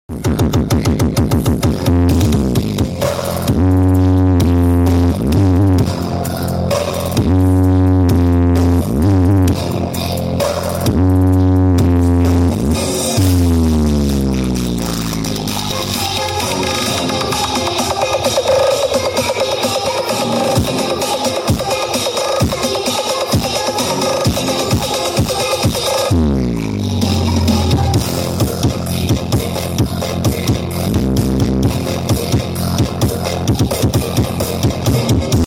Spesial Perfome Hajatan Live In sound effects free download